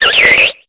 Cri de Ceribou dans Pokémon Épée et Bouclier.